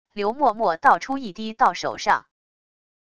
流墨墨倒出一滴到手上wav音频